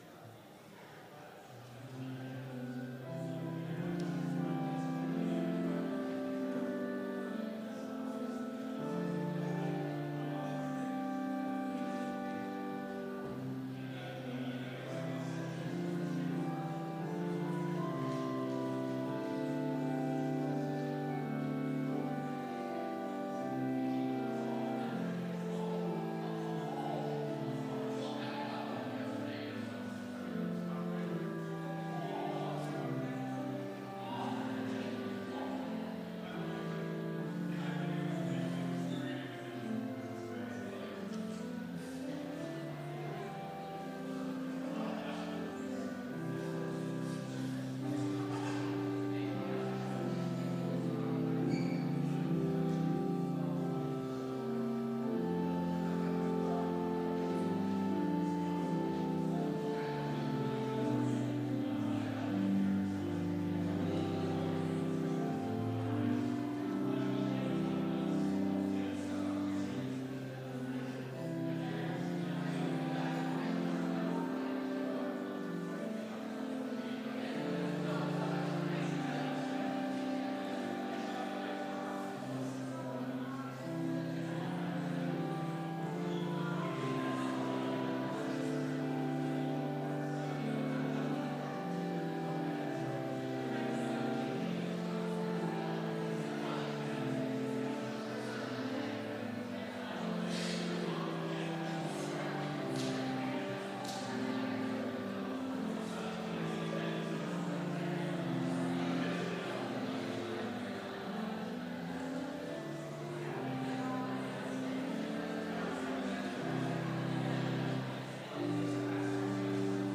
Complete service audio for Chapel - August 28, 2019